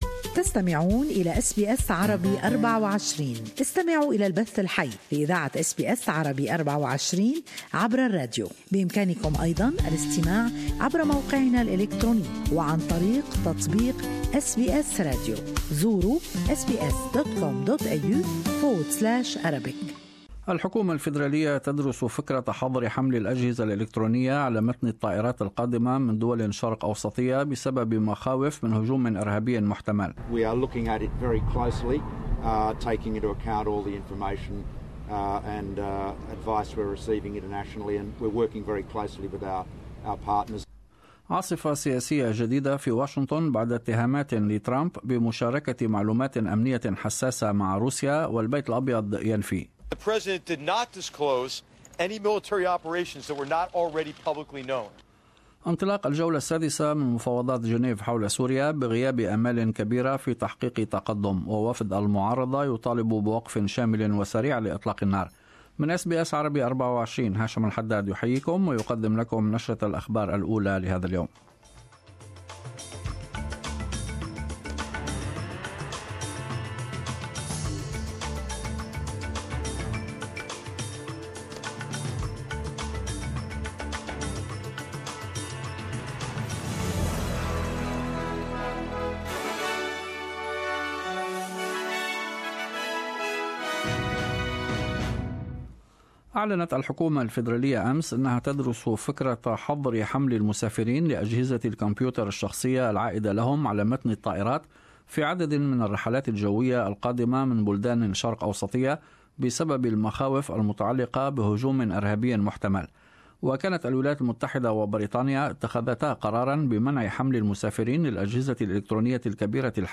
News Bulletin: Australia looking 'very closely' at potential aircraft laptop ban: Turnbull